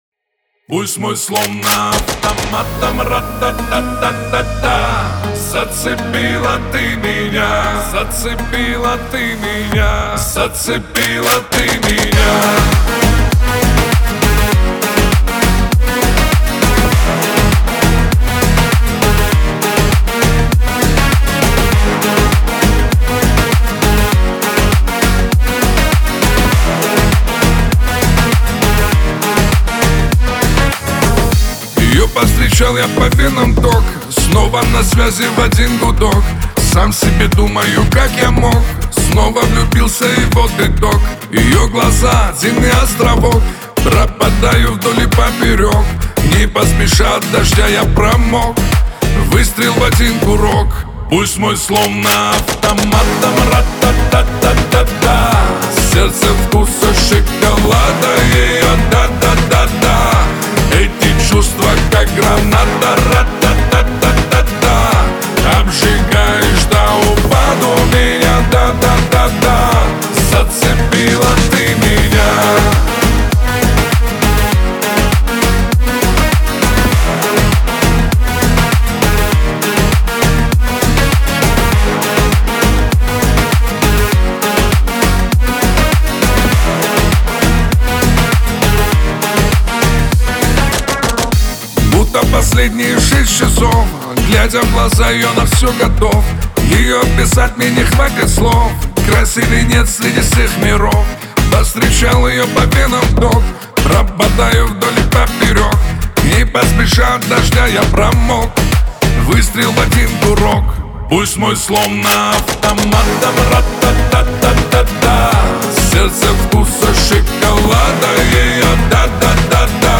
Качество: 320 kbps, stereo
Русские поп песни